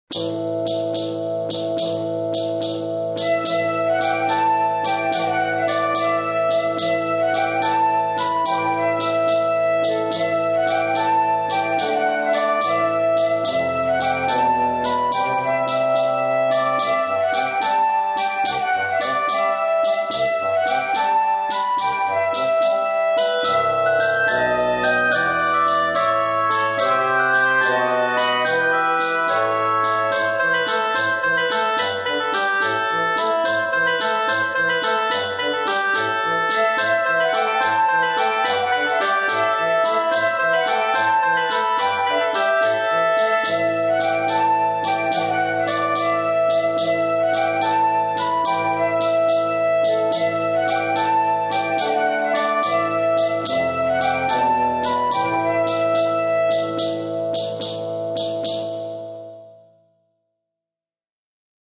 ~ САУНДТРЕК ~